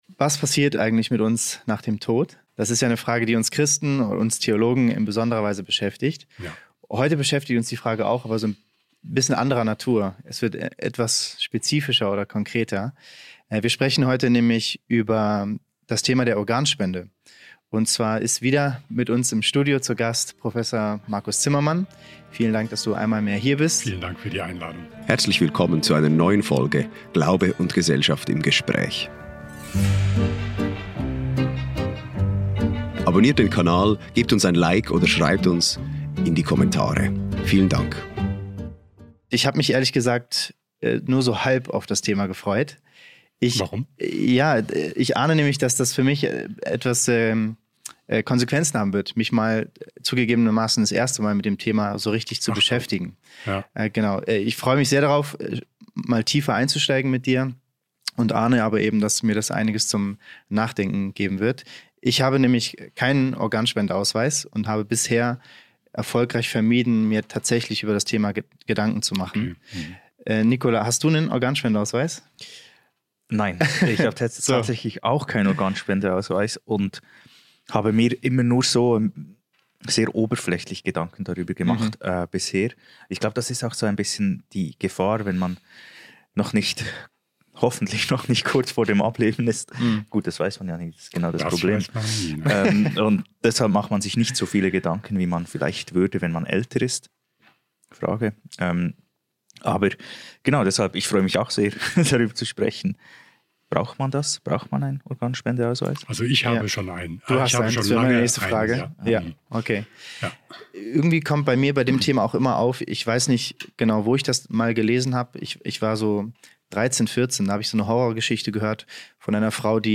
Ist die Organspende ein Akt der Nächstenliebe oder ein problematischer Eingriff in die leibliche Unversehrtheit? Darüber sprechen wir mit dem Präsidenten der Nationalen Ethikkommission im Bereich der Humanmedizin (NEK), Prof. Markus Zimmermann.